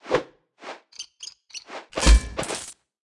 Media:Sfx_Anim_Super_Jessie.wavMedia:Sfx_Anim_Ultra_Jessie.wav 动作音效 anim 在广场点击初级、经典、高手和顶尖形态或者查看其技能时触发动作的音效
Sfx_Anim_Super_Jessie.wav